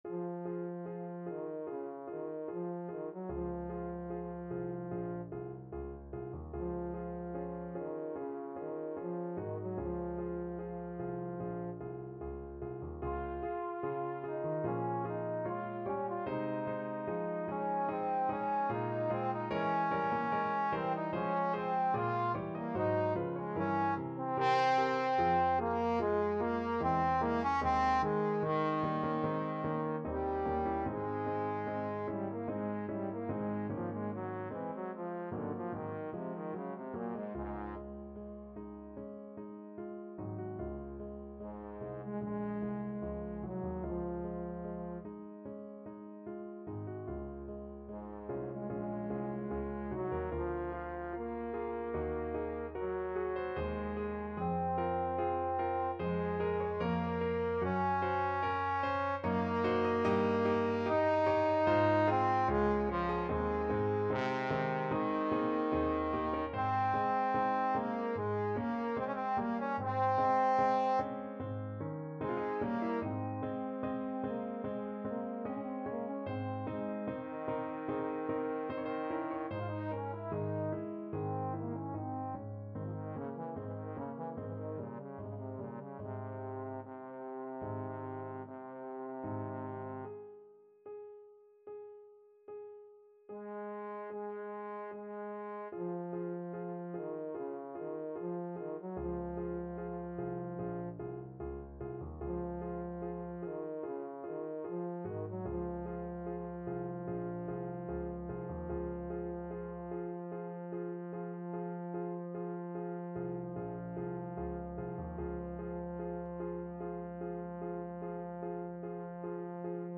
Trombone
Db major (Sounding Pitch) (View more Db major Music for Trombone )
~ = 74 Moderato
4/4 (View more 4/4 Music)
Ab3-F5
Classical (View more Classical Trombone Music)